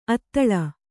♪ attaḷa